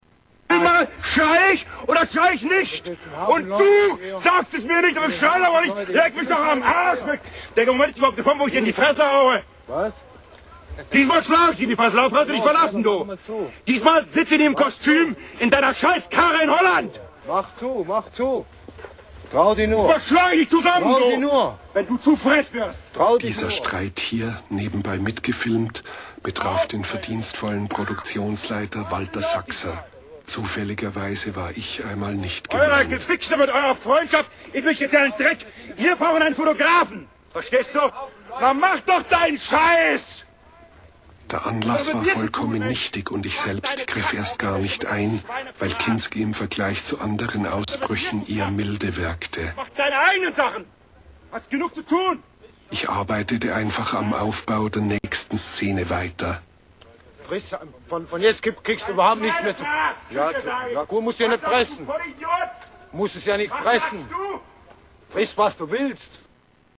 Fight with Actor Kinski on set of Fitzcarraldo